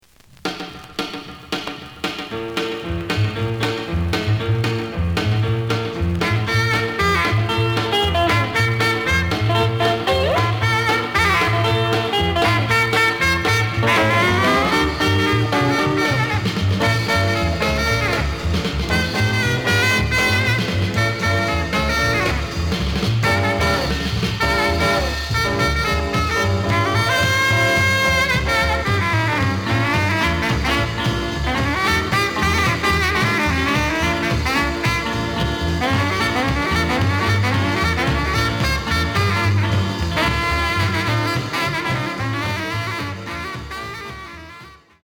The listen sample is recorded from the actual item.
●Genre: Rhythm And Blues / Rock 'n' Roll
B side plays good.)